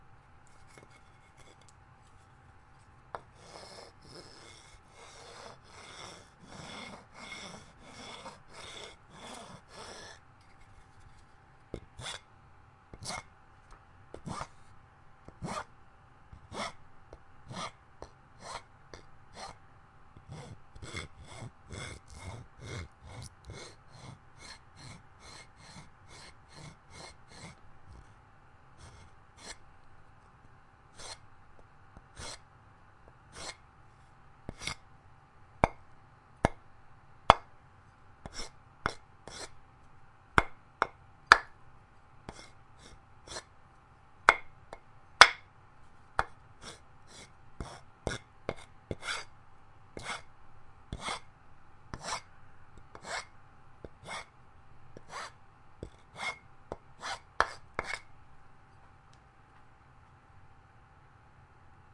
描述：用金属锉击打和刮擦2x4木板。
Tag: 打击乐 2×4 文件 木板 敲击 金属 命中 冲击